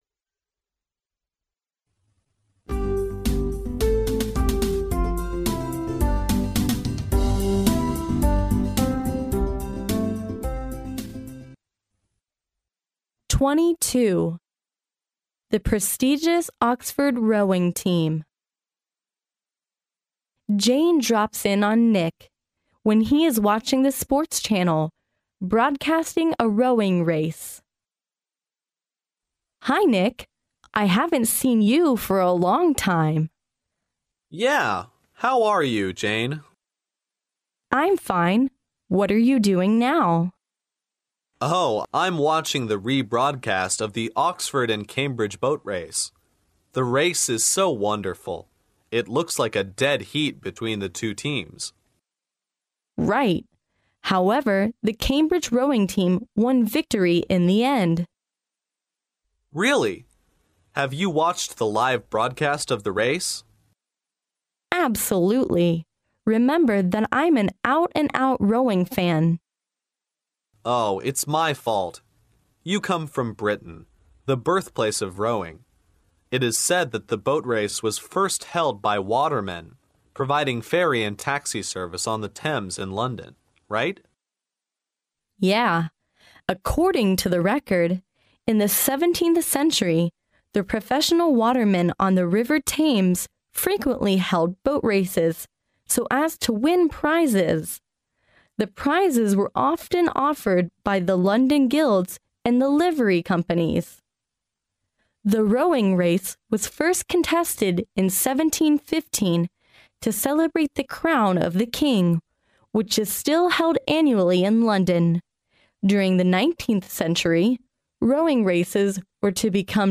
牛津大学校园英语情景对话22：久负盛名的牛津剑桥赛艇队（mp3+中英）